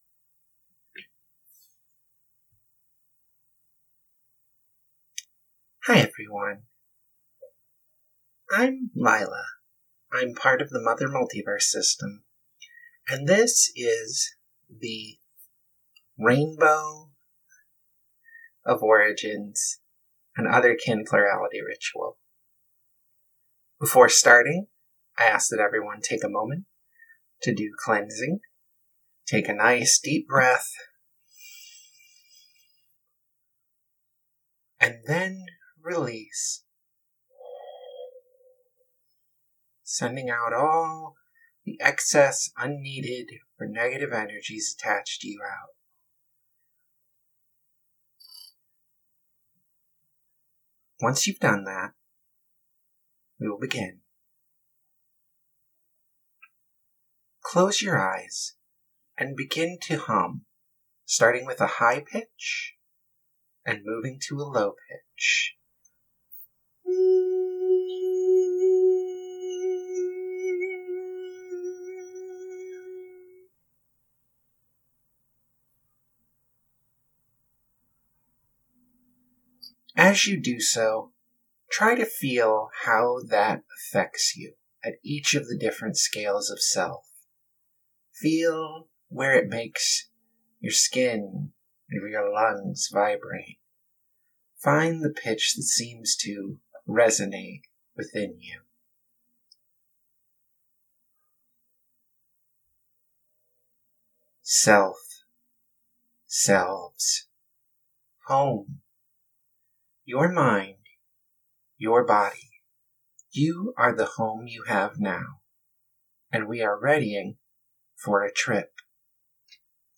Recording of the meditation portion only (not a live recording of the presentation): Recording in WAV format on Google Drive (202 MB) (Note: The volume level of this file is very quiet. The MP3 below has been amplified.)